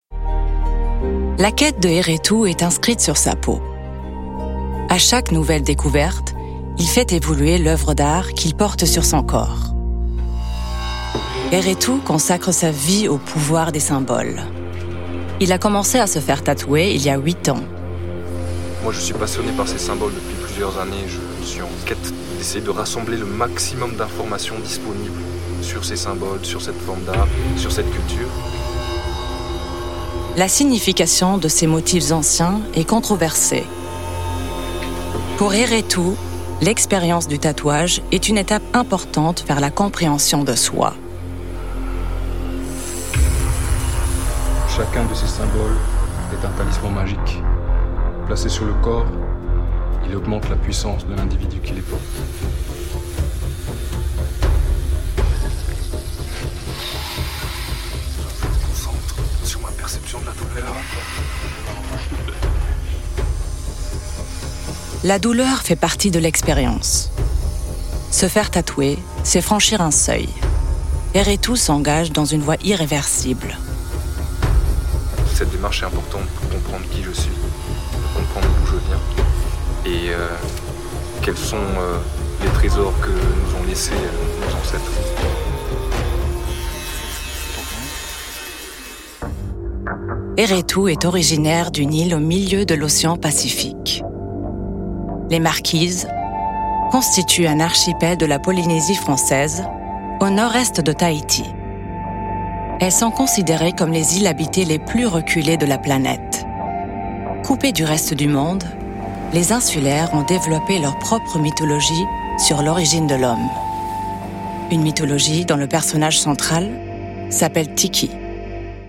Sprachproben
Sprecherin, Synchronsprecherin, Schauspielerin, Regisseurin